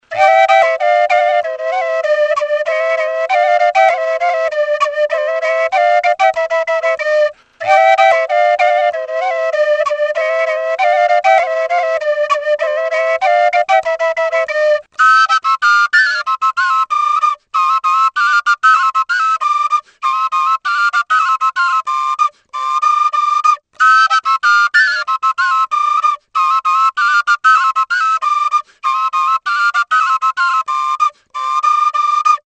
DVOJNICE; Flauta bikoitza | Soinuenea Herri Musikaren Txokoa